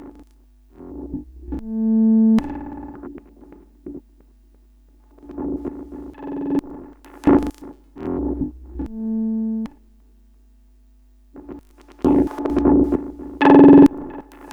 I would make a pattern using one track on the AR, then record it as a sample, then assign that sample to same track and repeat.